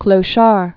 (klō-shär)